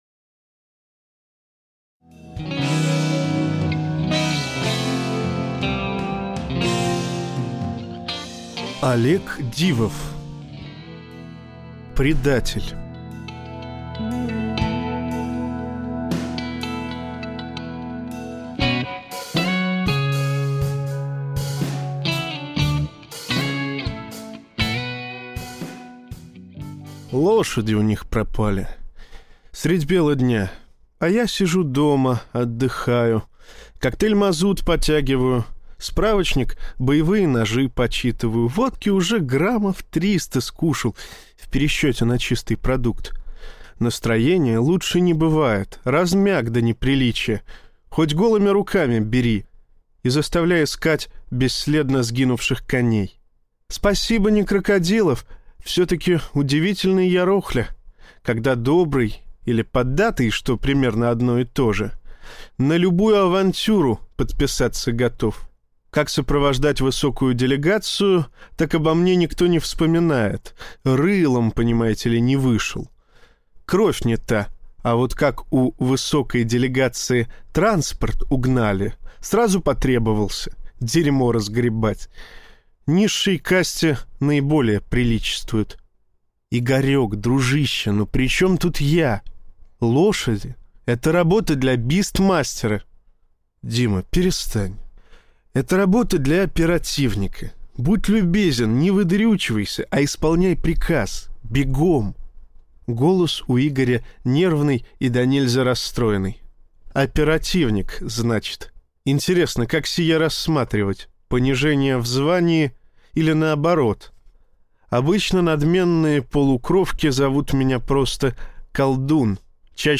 Аудиокнига Предатель | Библиотека аудиокниг